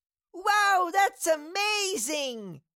Cartoon Little Child, Voice, Wow Thats Amazing Sound Effect Download | Gfx Sounds
Cartoon-little-child-voice-wow-thats-amazing.mp3